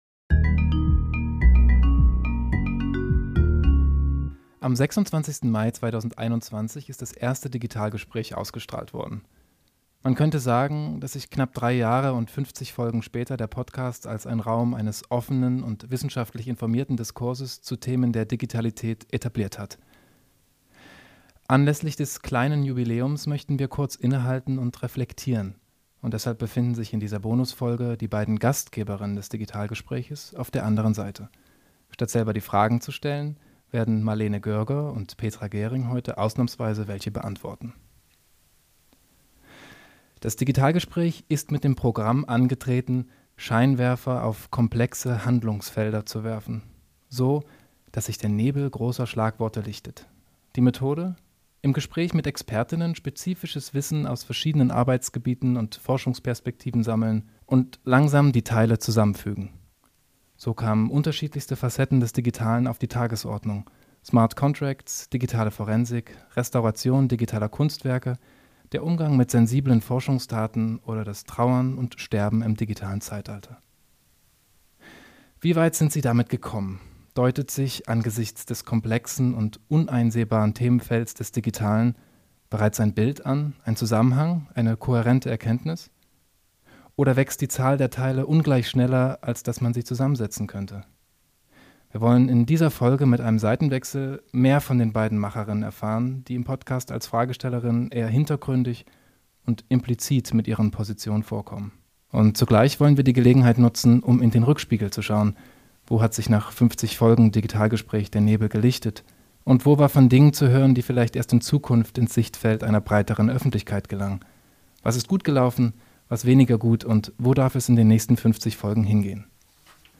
In der Bonusfolge kommen die Macherinnen im kleinen Darmstädter Aufnahmestudio